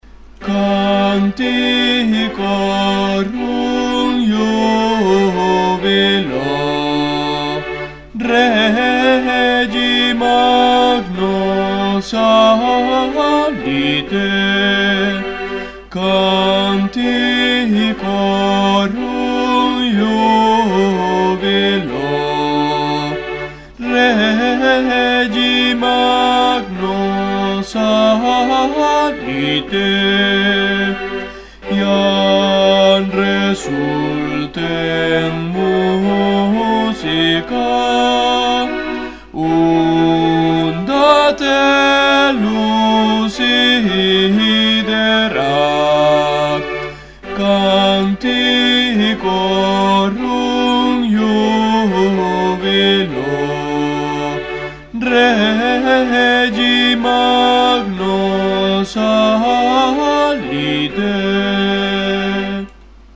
Musica SACRA Bajos
canticorum-bajos.mp3